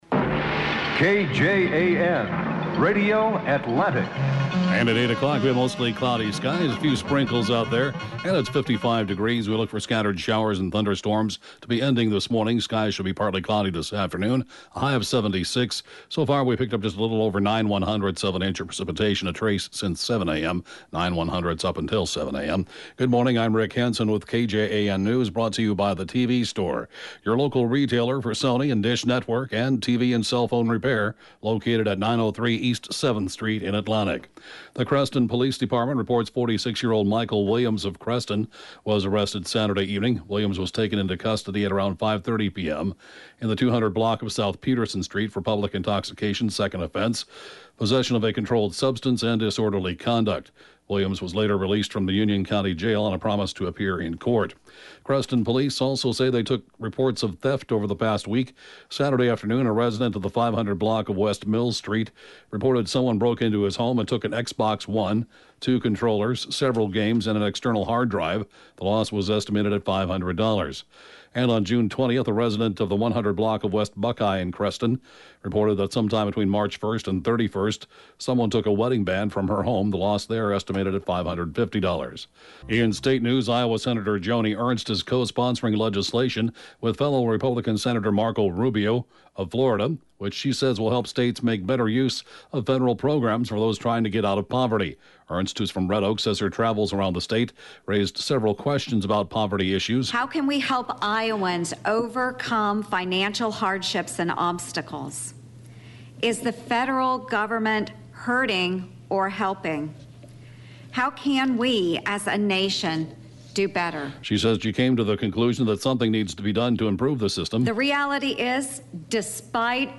(Podcast) KJAN 8-a.m. News, 6/26/2017